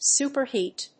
アクセント・音節sùper・héat